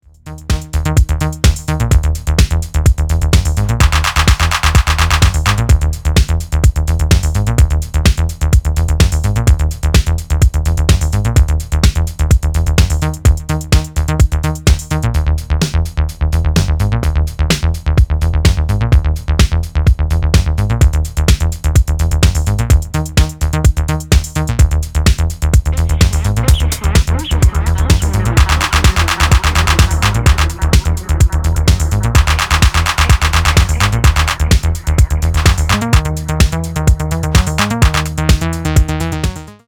シカゴ・アシッドにも通じる要素で淡々と展開を作る